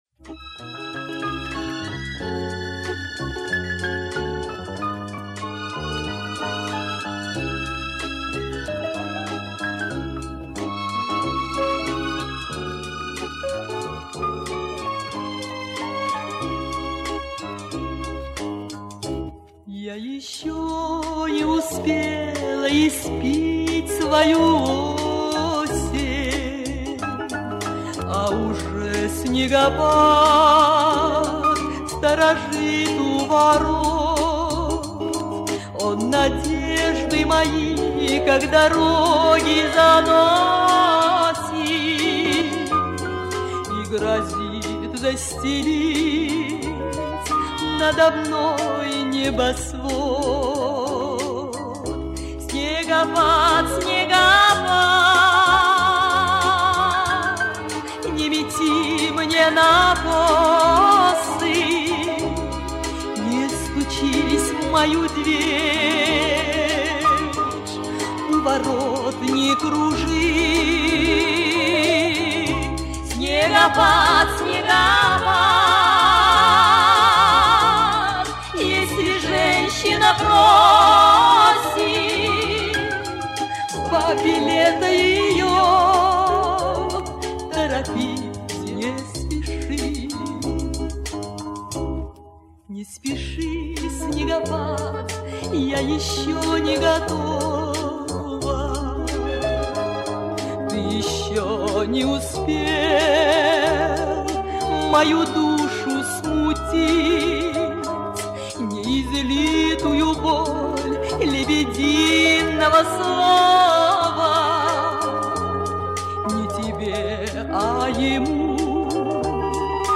один романс
Жаль только, качество записи хромает...